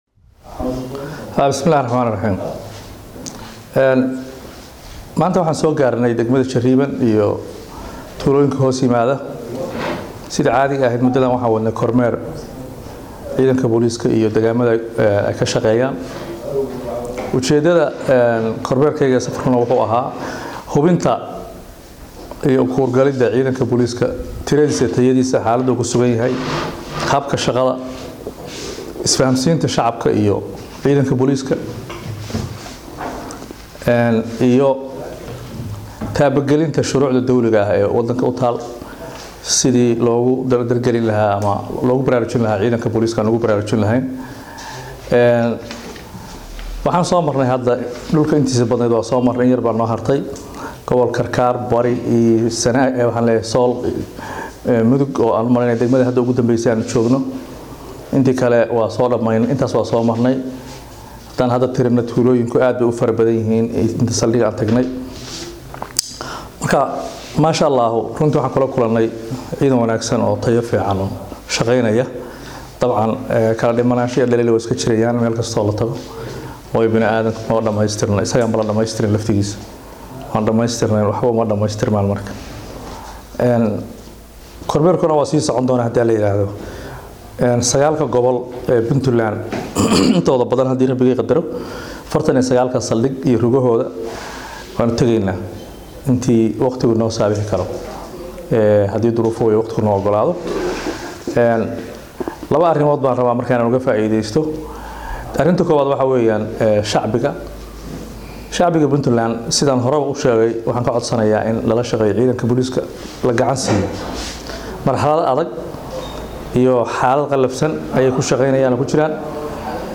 Dhagayso Taliyaha Ciidamada Booliska Puntland Jen Erag